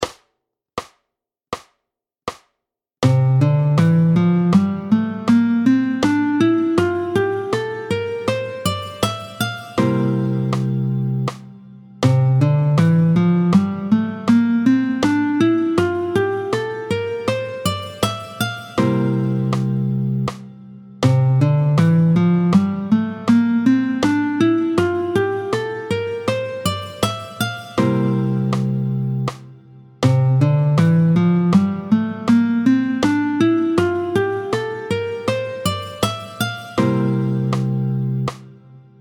Le mode (et le doigté V) : do ré mi fa sol la sib do, est appelé le Mixolydien.
27-05 Le doigté du mode de Do mixolydien, tempo 80
27-05-Do-Mixolydien.mp3